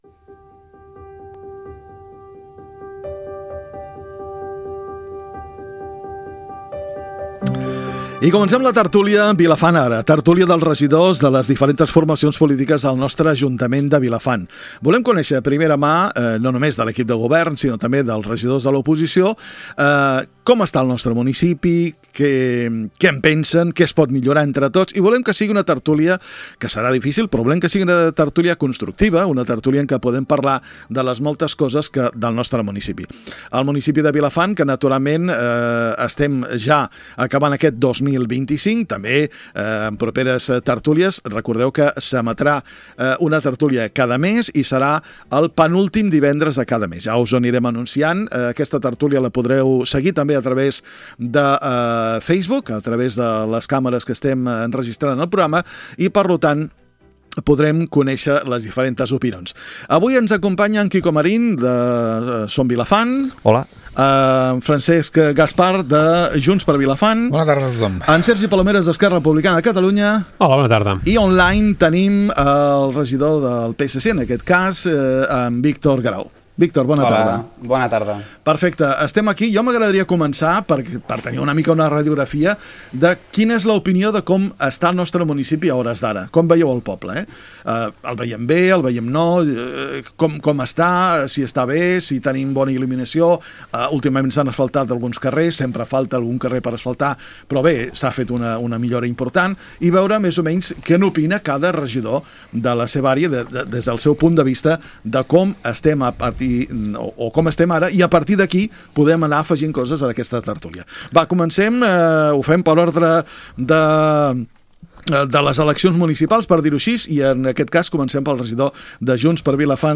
A Ràdio Vilafant , dins l’espai “Vilafant Ara” , hem fet una tertúlia amb els regidors de l’Ajuntament de Vilafant , amb representació tant del govern com de l’oposició . Una conversa que ha servit per conèixer els diferents punts de vista dels grups municipals sobre la gestió actual, els projectes en marxa i els reptes de futur del municipi.